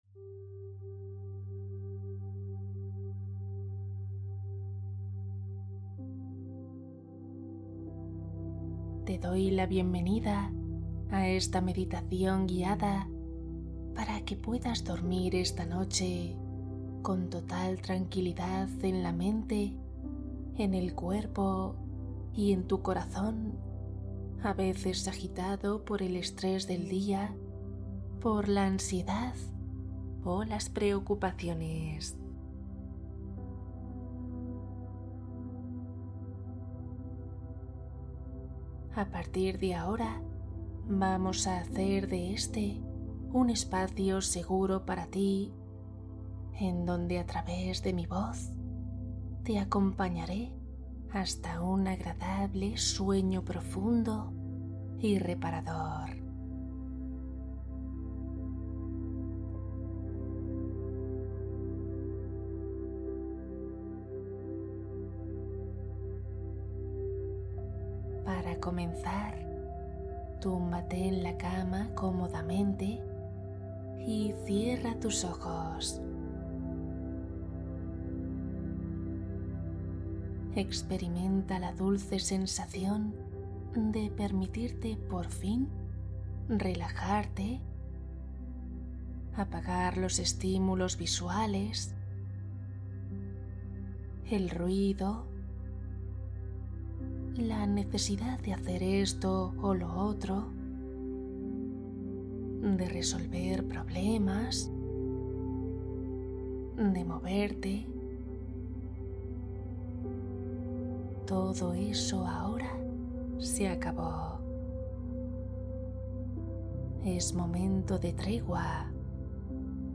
Duerme conmigo esta noche Meditación para dormir rápido y descansar profundo